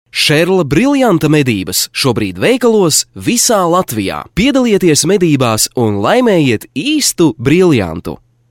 Sprecher lettisch für TV / Rundfunk / Industrie / Werbung.
Sprechprobe: Industrie (Muttersprache):
Professionell voice over artist from Latvia.